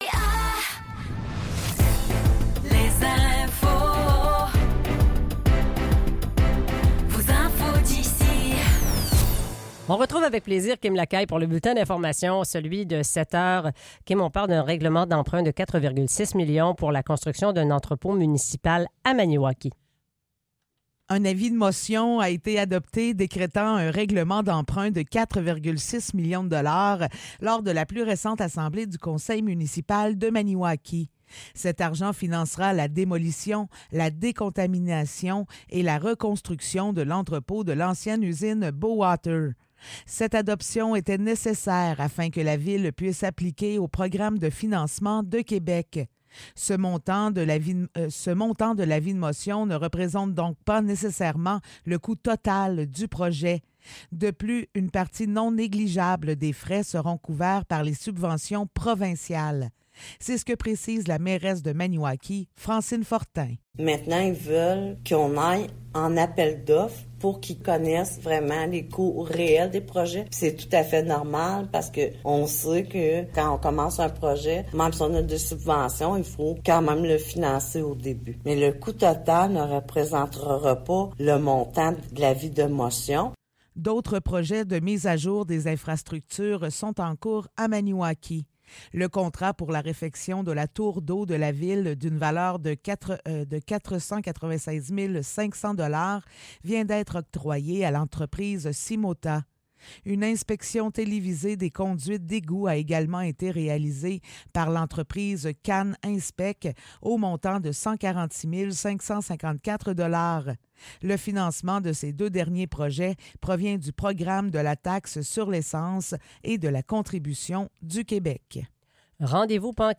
Nouvelles locales - 6 juin 2024 - 7 h